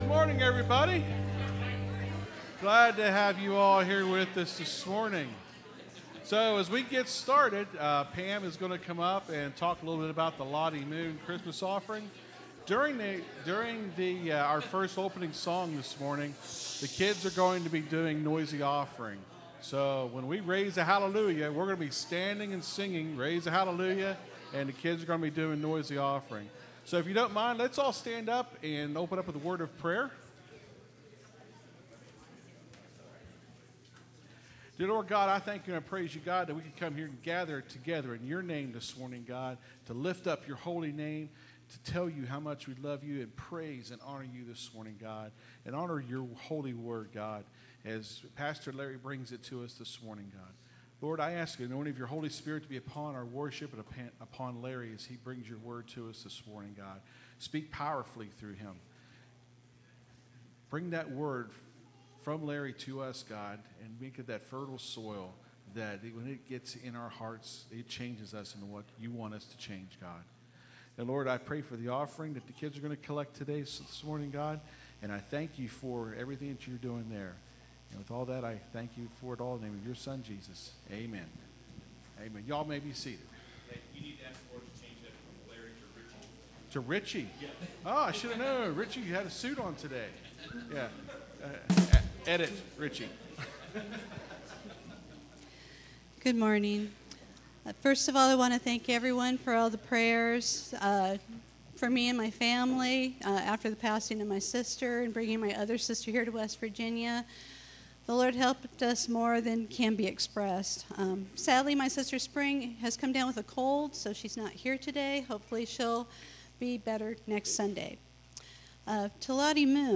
(Sermon starts at 28:50 in the recording).